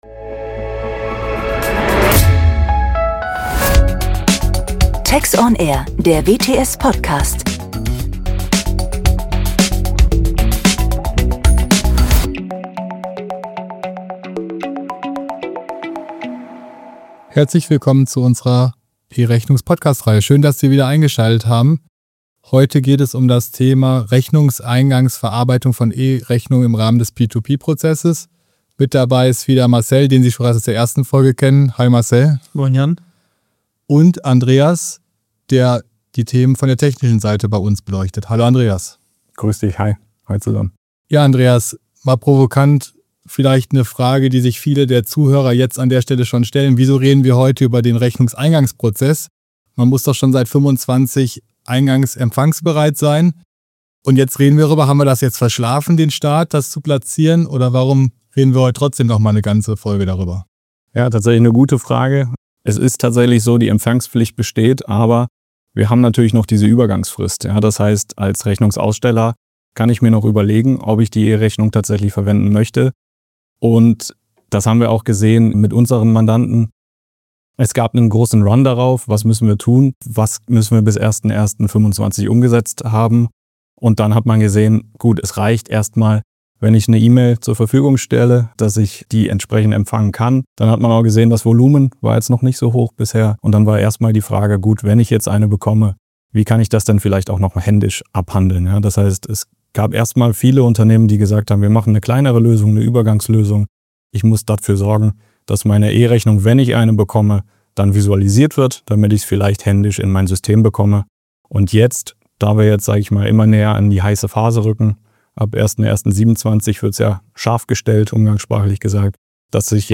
Gemeinsam tauschen sie sich zu ihren Erfahrungen rund um die E-Rechnungsempfangspflicht aus und gehen auf aktuelle Herausforderungen, mögliche Lösungen, Lieferantenbeziehungen und Compliance-Themen ein. Zum Schluss geben sie einen praktischen Ausblick sowie mögliche Optimierungspotenziale. Die Podcast-Reihe „Einfach E-Rechnung“ beleuchtet im Rahmen von kurzweiligen Expertengesprächen neben den aktuellen rechtlichen Entwicklungen und Rahmenbedingungen in Deutschland auch viele technische und prozessuale Fragestellungen im Rechnungseingangs- und Rechnungsausgangsprozess.